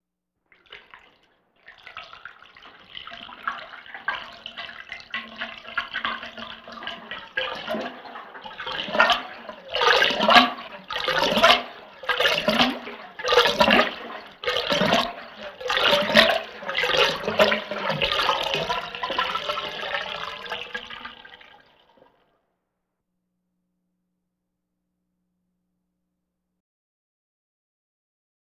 Sink Drain Emptying Sound
household